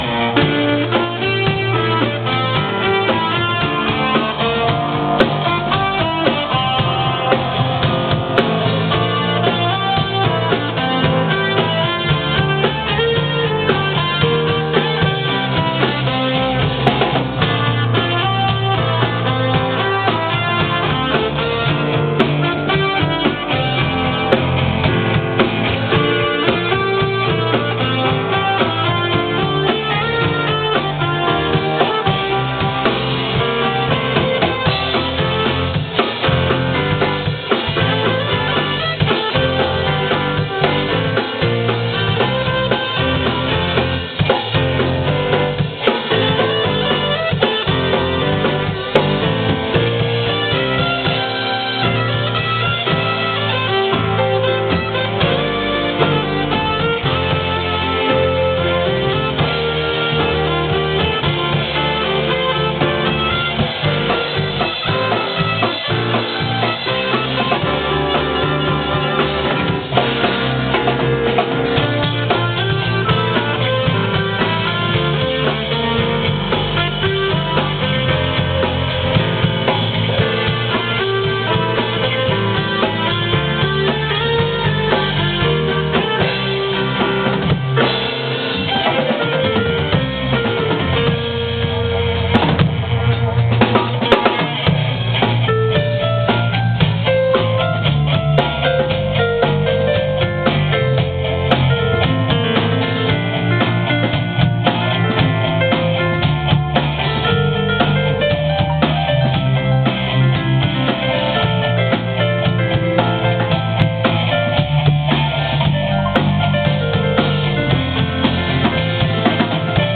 EWI연주
2007-08-01 오전 12:06:00 밴드 연습할때 녹음해본겁니다.
EWI4000s 02번 소리 사용했습니다.